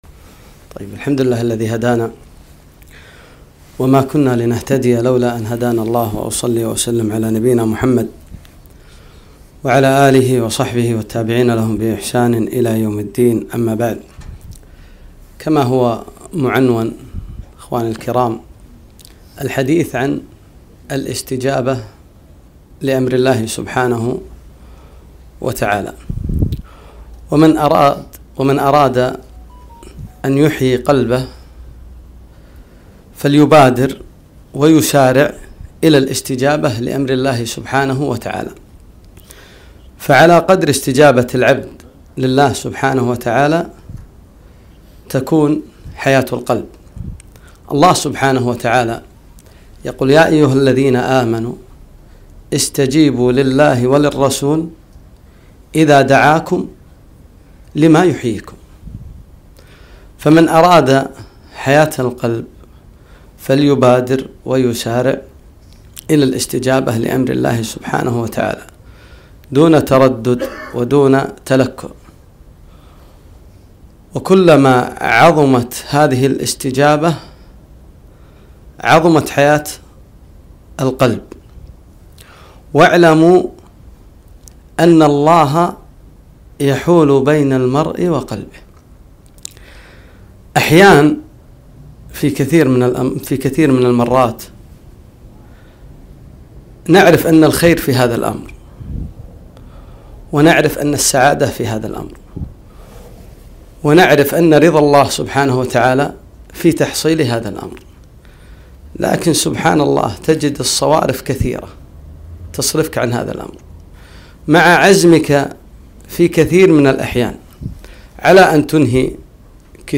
كلمة - الاستجابة لأمر الله عز وجل